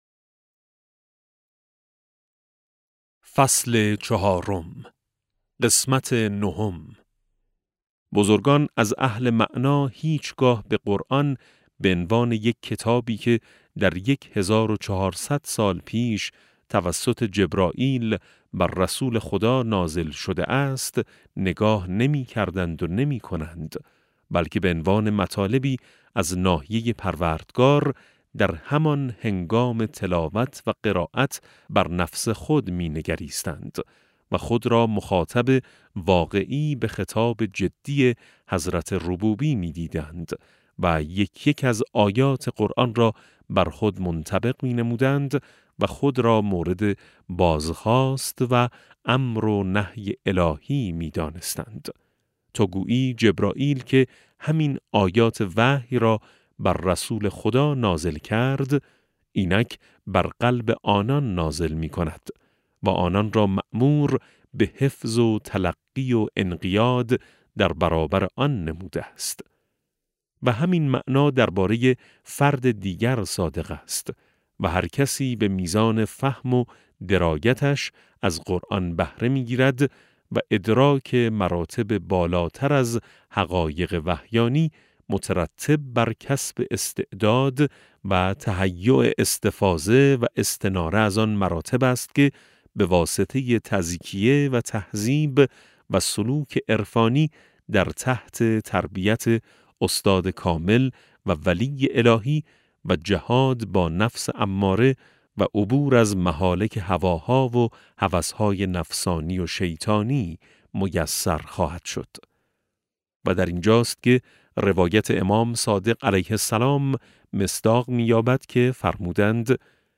افق وحی - فصل چهارم (425 ـ 439) کتاب صوتی افق وحی - جلسه 24 پدیدآور آیت‌اللَه سید محمدمحسن حسینی طهرانی توضیحات افق وحی - فصل چهارم: بررسی مطالب مطرح شده از طرفین - صفحه (425 ـ 439) متن این صوت دانلود این صوت